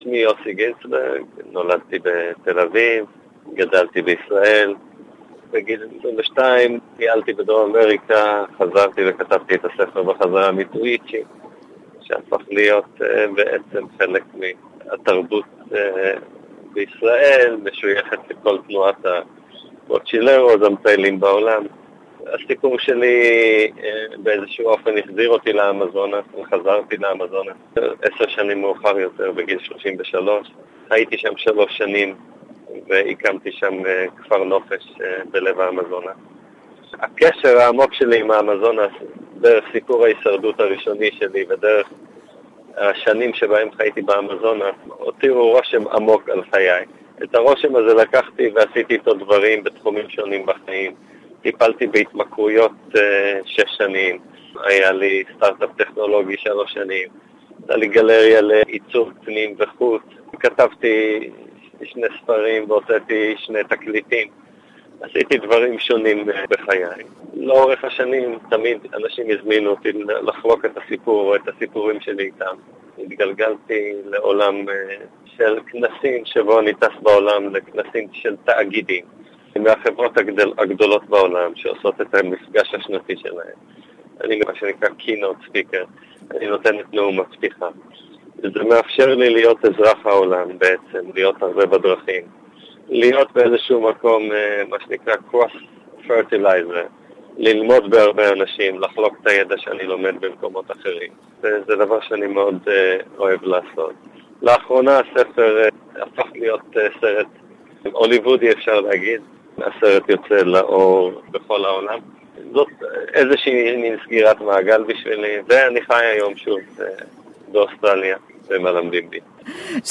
Yossi Ghinsberg: "The story "Jungle" is not mine, I gave it to the world"...(Hebrew intrview)